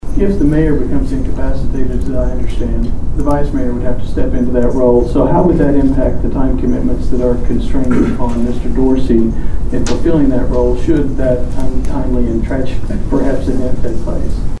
Curd, Dorsey Appointed Mayor, Vice-Mayor in Contentious City Council Meeting
Sherrick Questioning Dorsey's Time 12-2.mp3